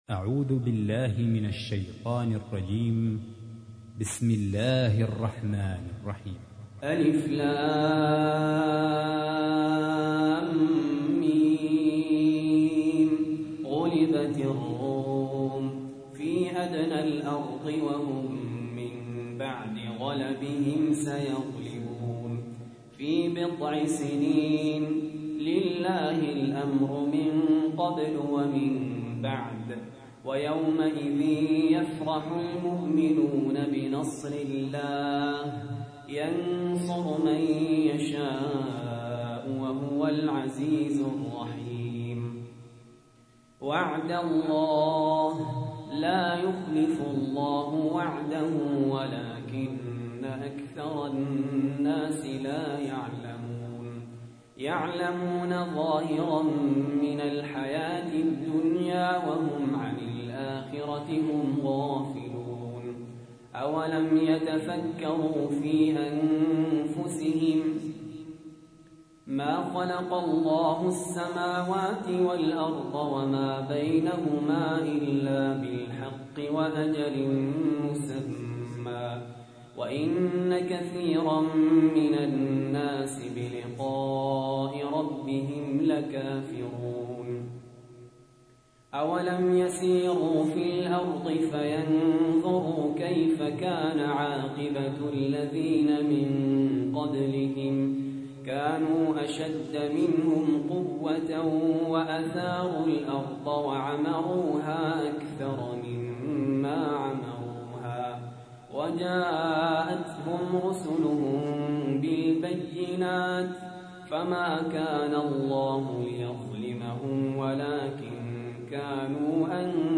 تحميل : 30. سورة الروم / القارئ سهل ياسين / القرآن الكريم / موقع يا حسين